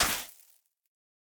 Minecraft Version Minecraft Version 1.21.5 Latest Release | Latest Snapshot 1.21.5 / assets / minecraft / sounds / block / suspicious_sand / step5.ogg Compare With Compare With Latest Release | Latest Snapshot
step5.ogg